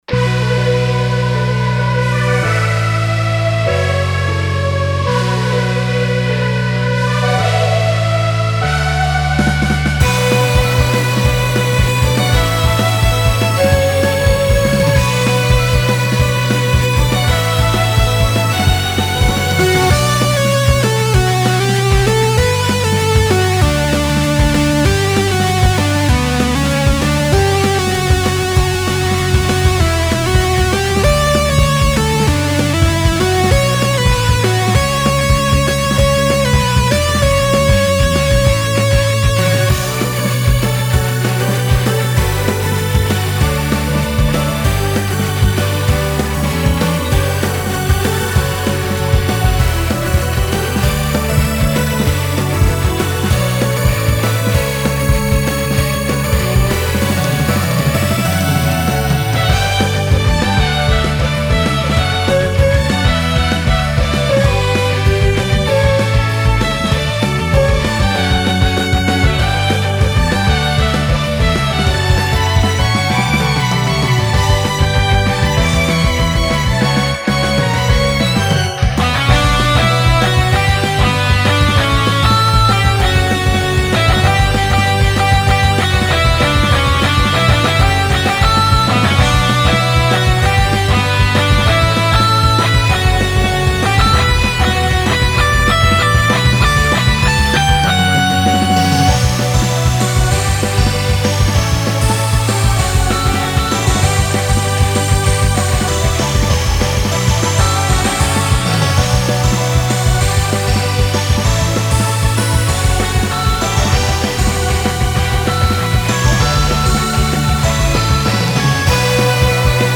フリーBGM フィールド・ダンジョン フィールド
フェードアウト版のmp3を、こちらのページにて無料で配布しています。